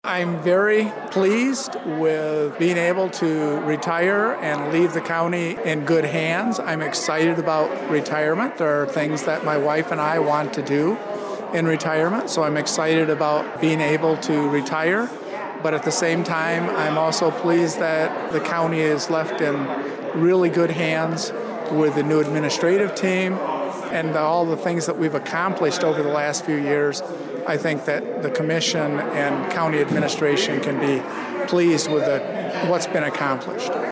Adrian, MI – Family, friends, and the Lenawee County community gathered at the newly-renovated Old Courthouse in Adrian Wednesday afternoon to celebrate the retirement of County Administrator Marty Marshall.
Marty talked to WLEN News about how he felt about retirement…now that the day is here…